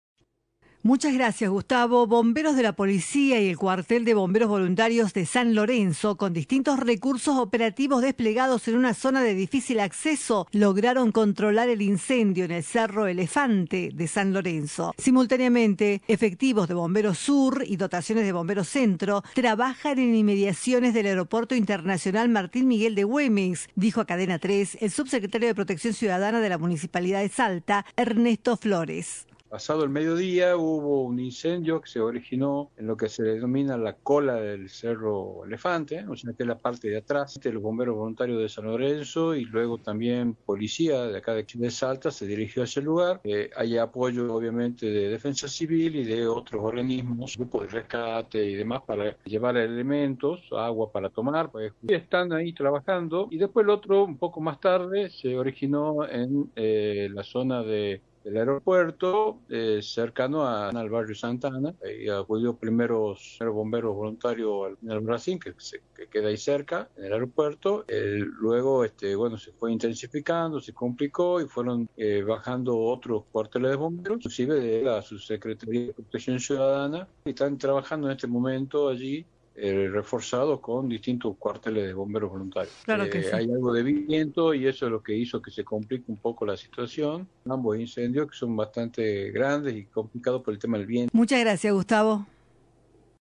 El 23 de enero de 2021, Defensa y Justicia goléo 3-0 a Lanús en el estadio Kempes y ganó su primer título internacional. Reviví la consagración en el relato